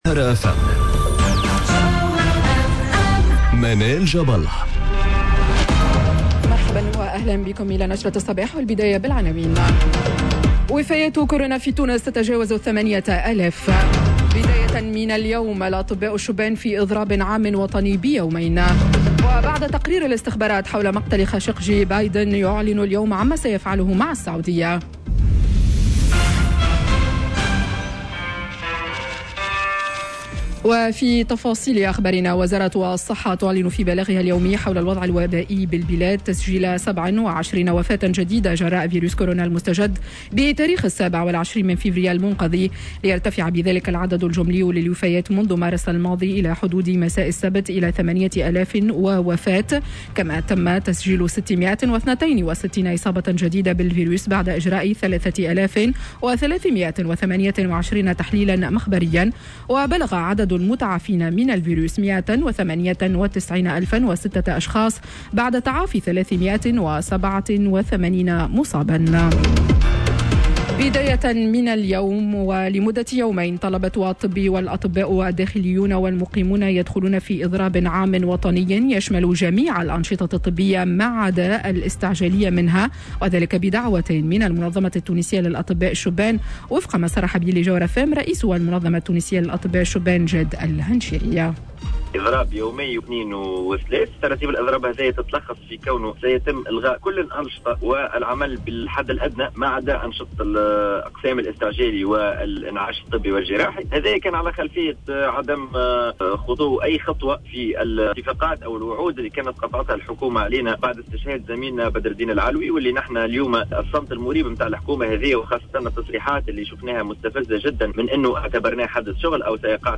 نشرة أخبار السابعة صباحا ليوم الإثنين 01 مارس 2021